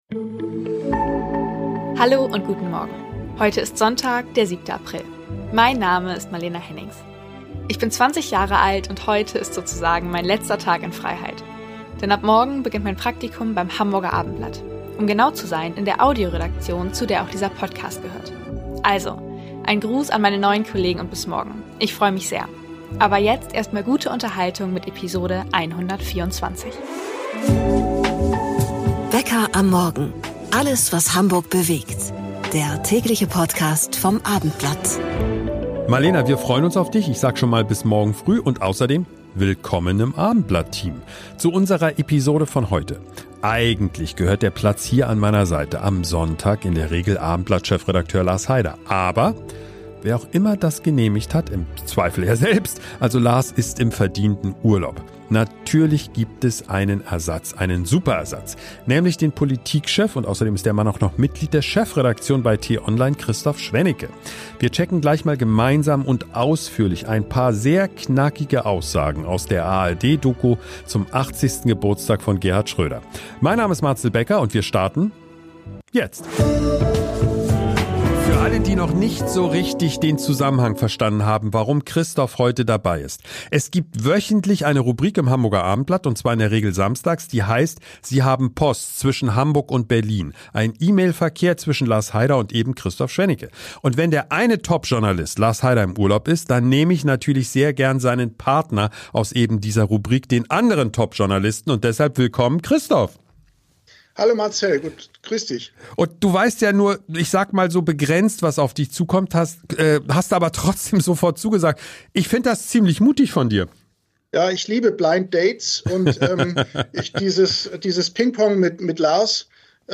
- Altkanzler wird 80 und teilt ordentlich aus - Die besten Sequenzen aus der der ARD-Doku bei uns